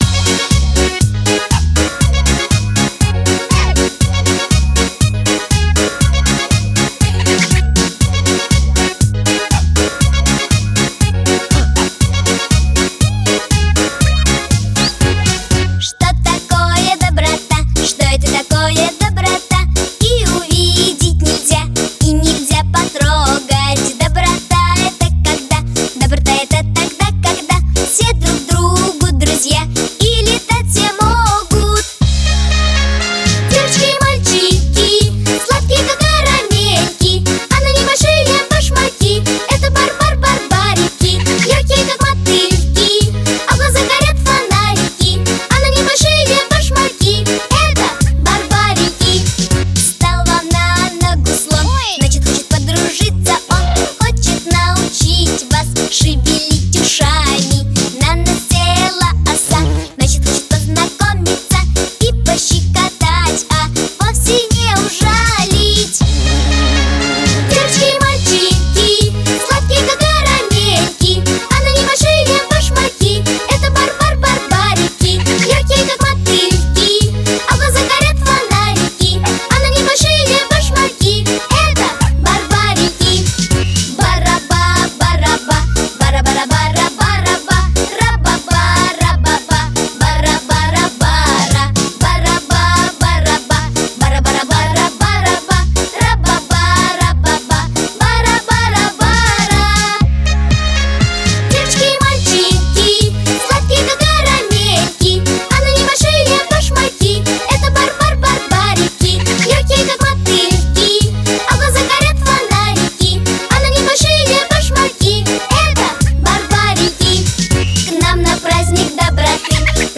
детская музыка